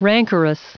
Prononciation du mot rancorous en anglais (fichier audio)
Prononciation du mot : rancorous